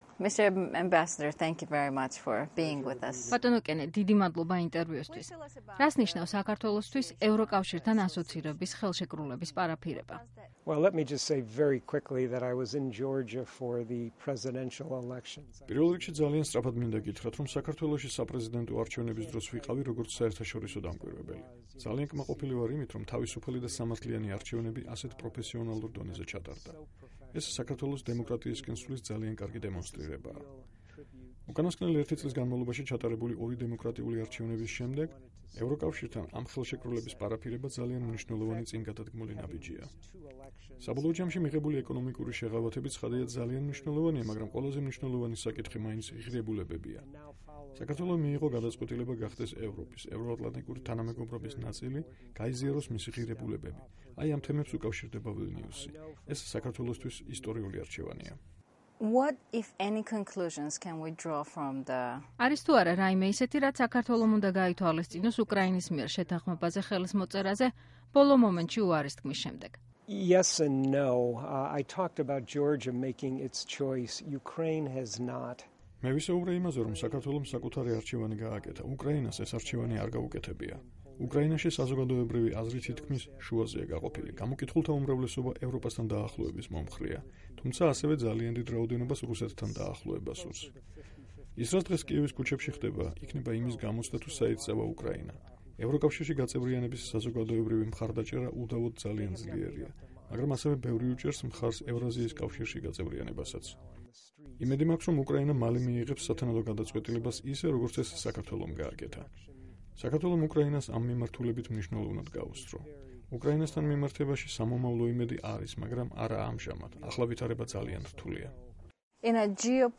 ინტერვიუ კენეტ იალოვიცთან